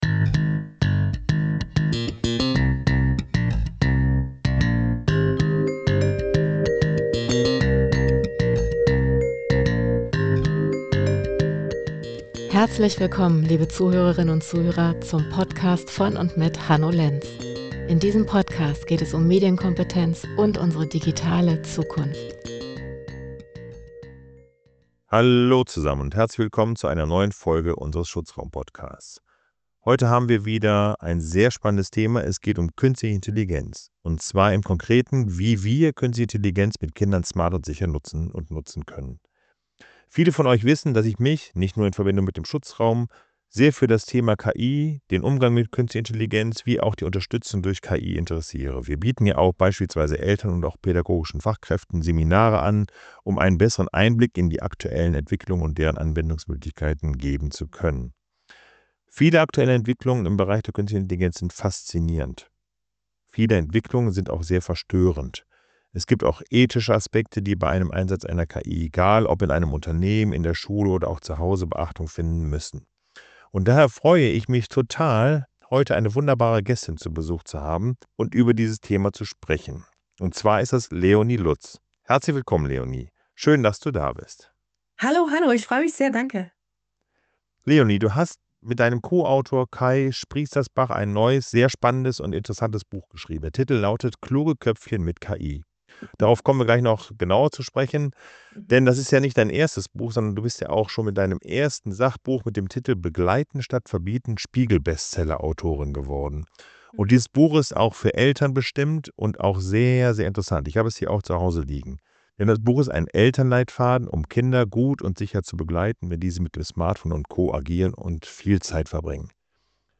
Im Gespräch: Kluge Köpfchen mit KI ~ SCHUTZRAUM - unsere digitale Zukunft Podcast